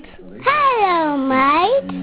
Here is the Windows startup sound I have had for a while to remind me of home when I am away.
hellomate.wav